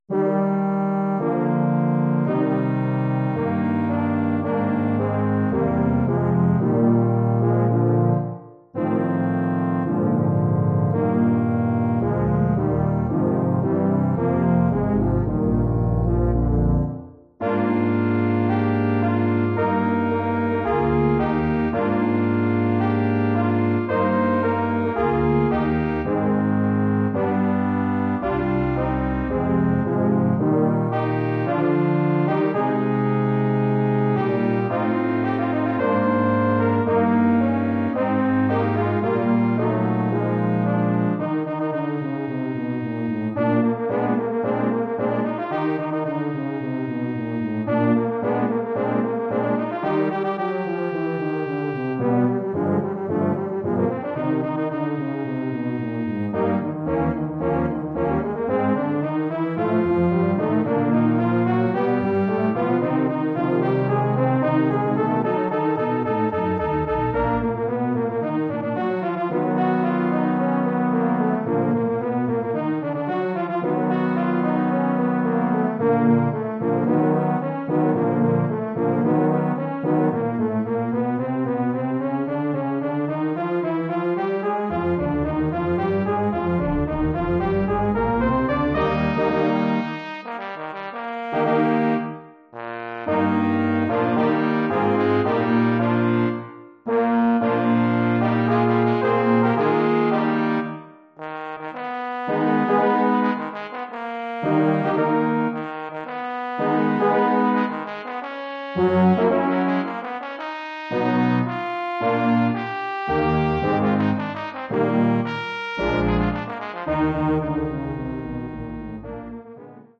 Bearbeitung für Posaune und Hornquartett
Besetzung: Posaune, 4 Hörner
arrangement for trombone and horn quartet
Instrumentation: trombone, 4 horns